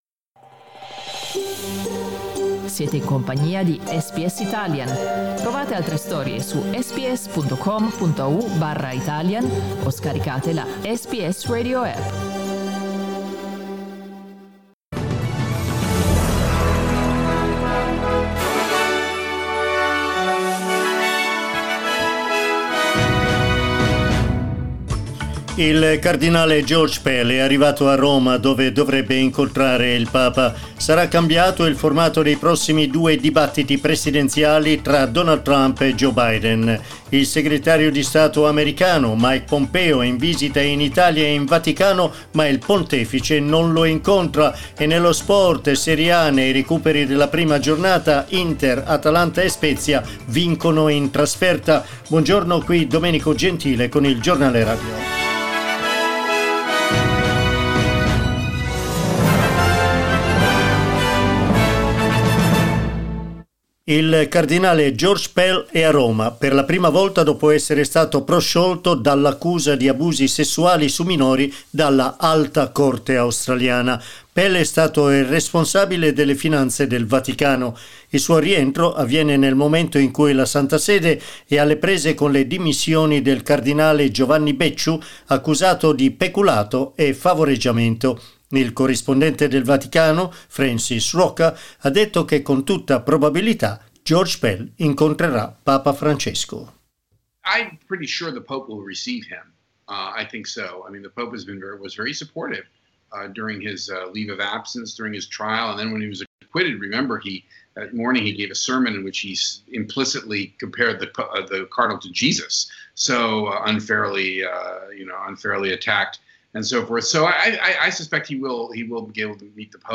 Il notiziario in italiano delle nove di questa mattina.